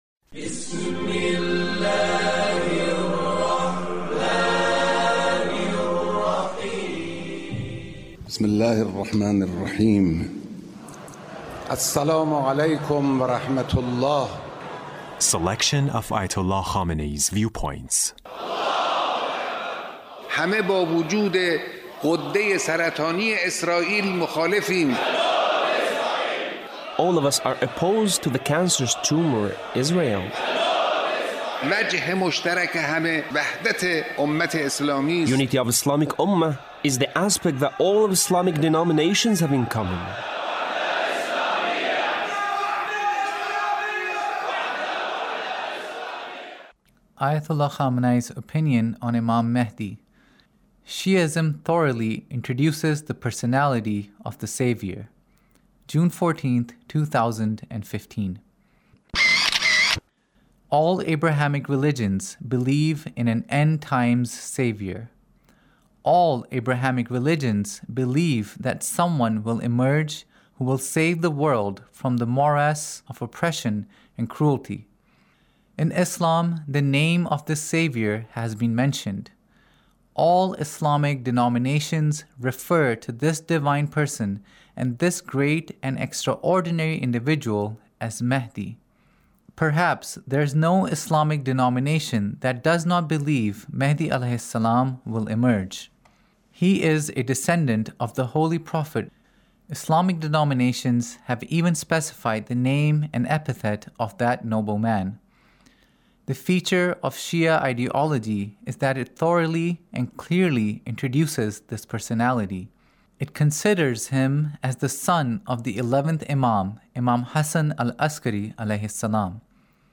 Leader's Speech (1890)